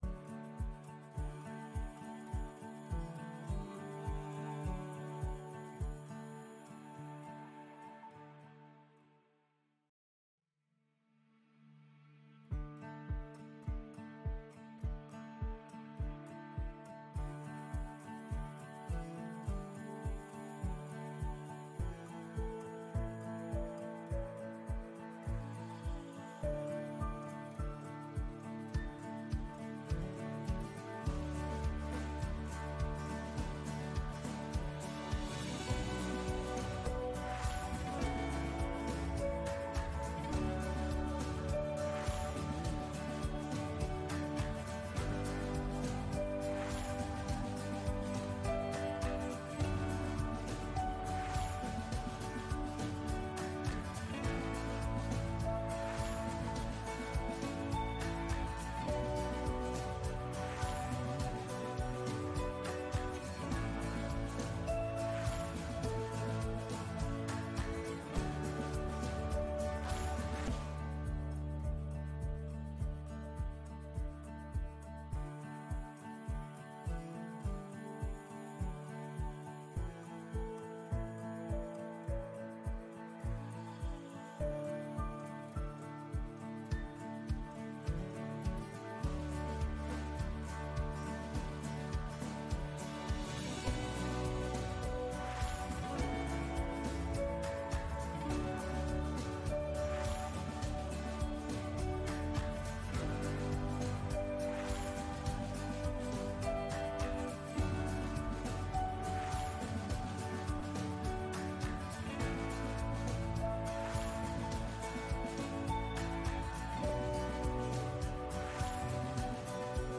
Unsere Livestream Gottesdienste - Christuskirche Gottesdienstechristuskirche-gottesdienste
Videos und Livestreams aus der Christuskirche Hamburg Altona (Baptisten)